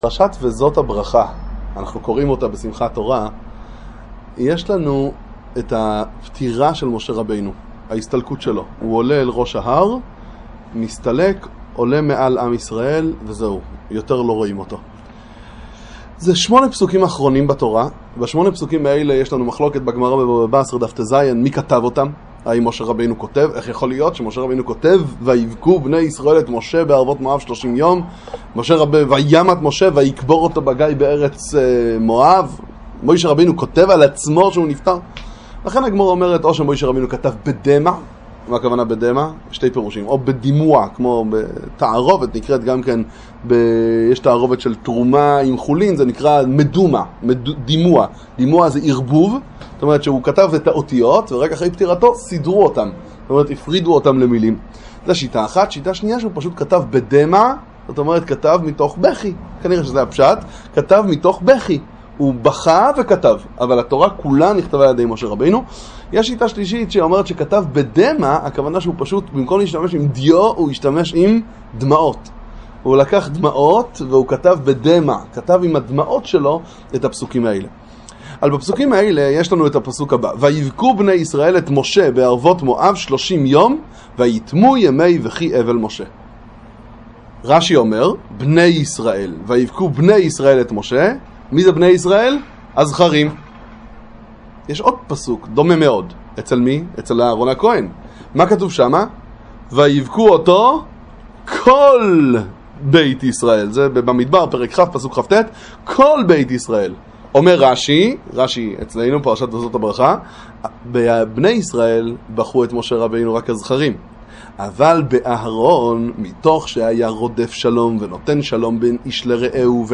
שיעור חדש